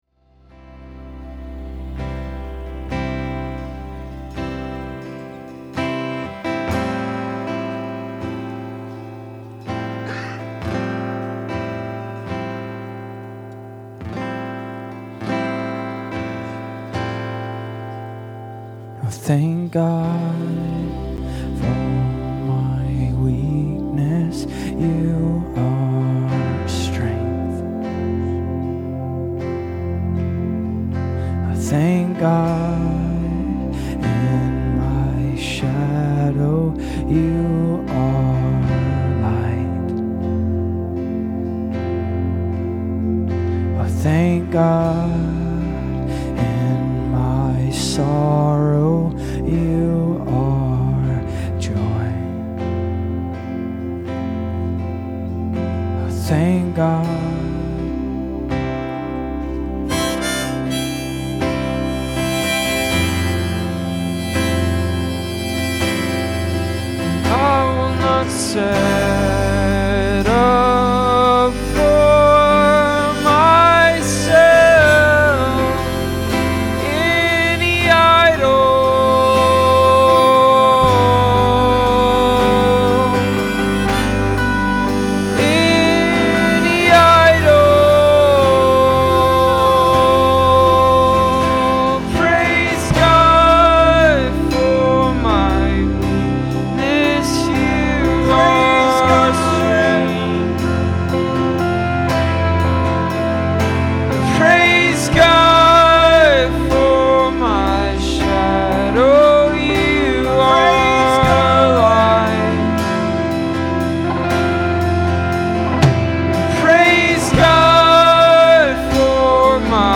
This is a raw live recording of a song we wrote earlier this year, here is the mp3 and the chart… Enjoy!
This is one of my favorite praise and worship songs.
amazing song, thanks…love the harmonica!!!